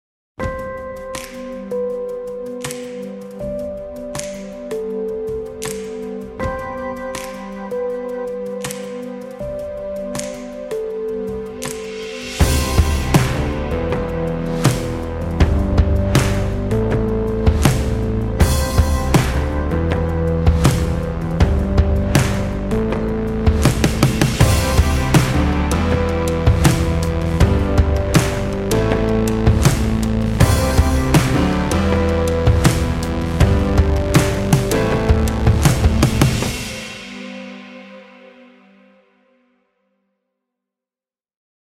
This library was recorded in a variety of spaces — three recording studios, a living room, hallways, foyers, a dining room, bedroom, and a 7500 sq ft warehouse.
816 individual one-shot and multiple velocity samples, 29 bangs and hits, 11 booms, 70 claps in big + small spaces, 40 studio and living room kick in 6 variations, 186 warehouse kicks in 4 variations, 13 reverses, 23 rims + clicks, 24 slaps, 41 snaps in big + small spaces, 264 snares in 4 variations, 45 stomps in big + small spaces, 18 swells, 40 toms in 3 variations, 12 toys
nearly 400 loops in multiple variations, tempos ranging from 72 – 124 bpms, variety of grooves and feels